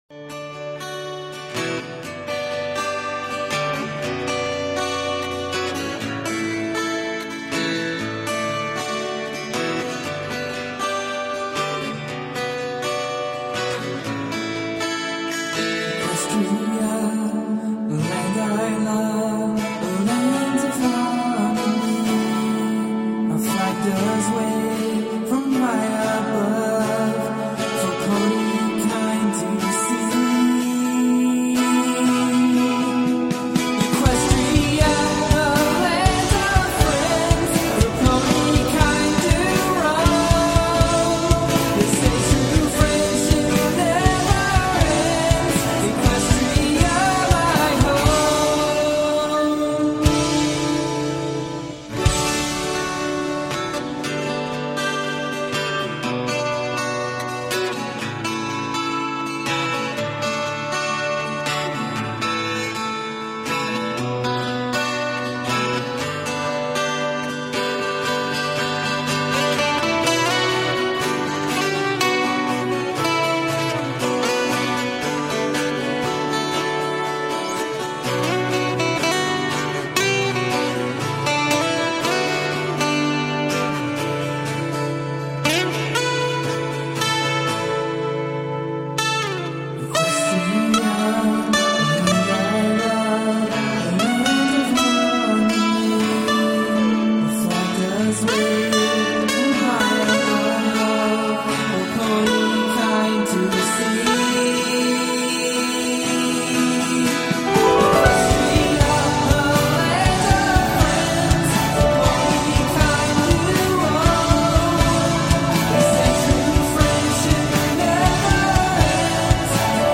This was fun, even if I can't sing.